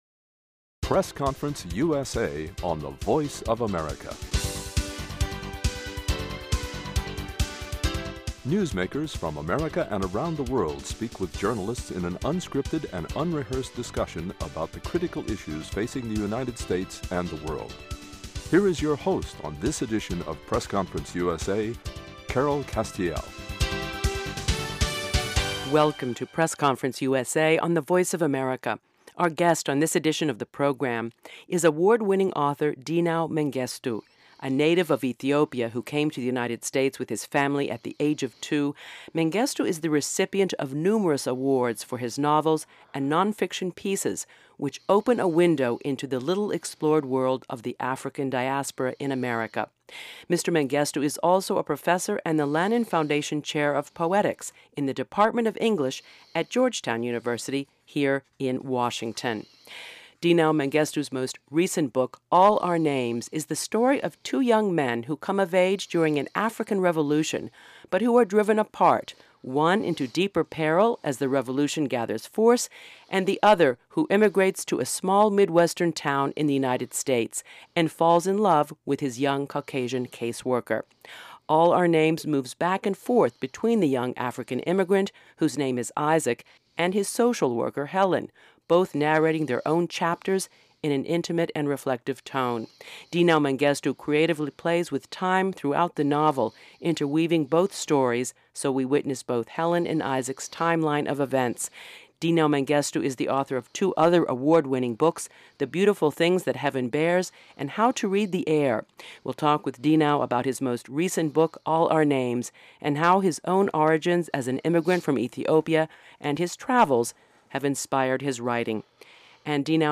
talks with award-winning author Dinaw Mengestu